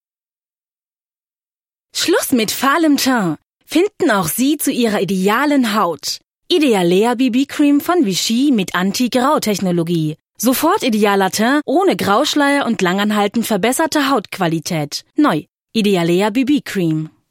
junge Stimme, variabel, dynamisch, sinnlich
Kein Dialekt
Sprechprobe: Werbung (Muttersprache):